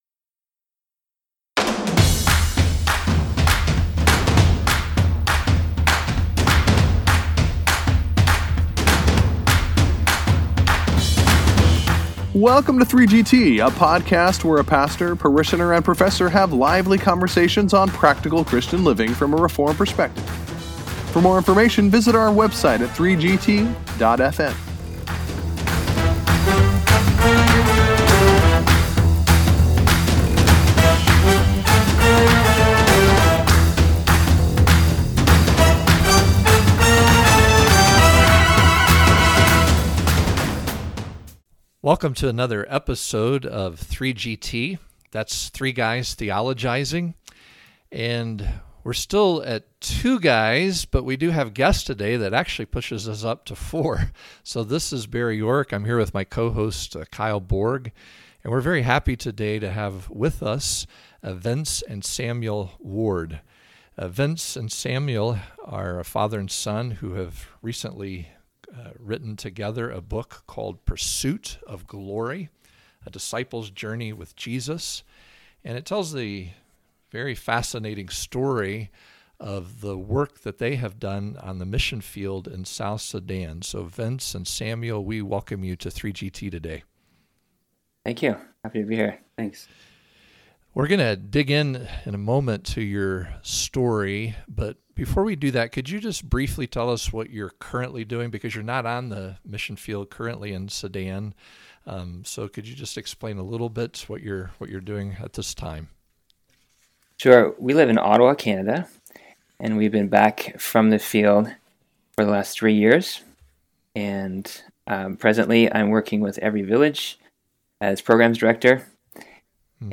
Episode 89: Interview